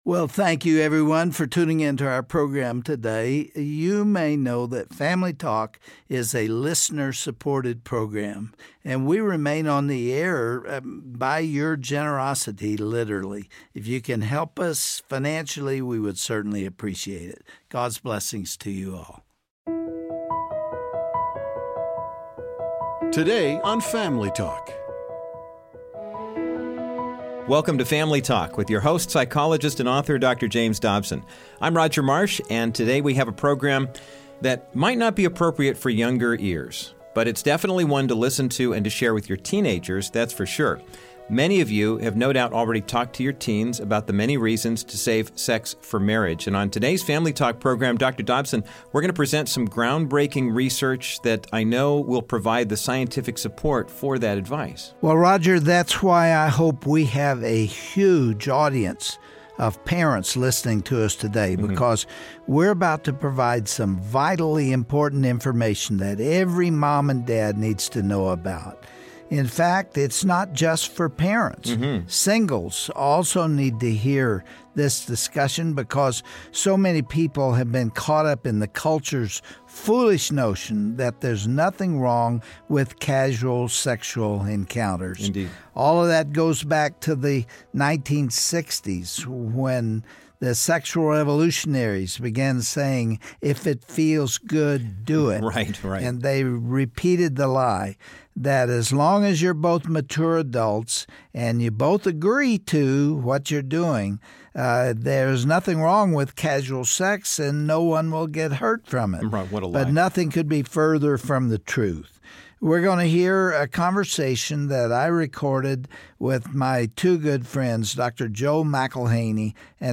On this broadcast , it's straight talk for all parents wanting to teach their kids the importance of saving intimacy for marriage. Dr. James Dobson sits down with two OB-GYNS to discuss the latest scientific research supporting God's design for sexual purity.